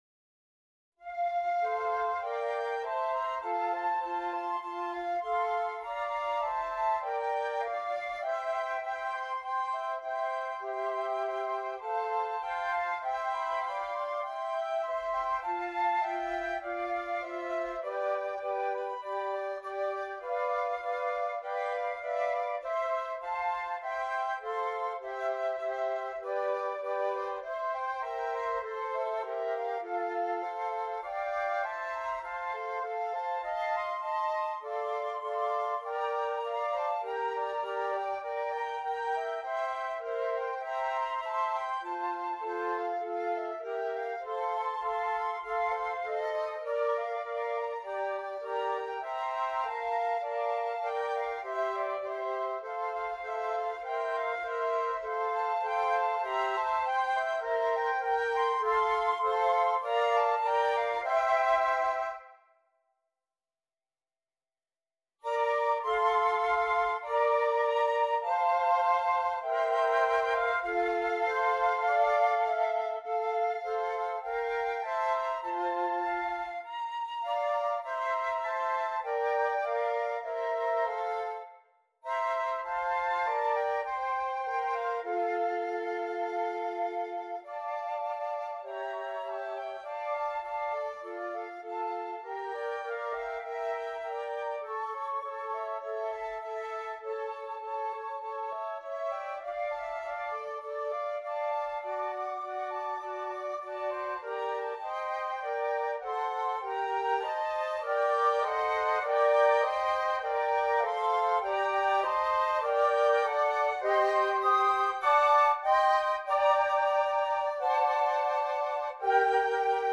4 Flutes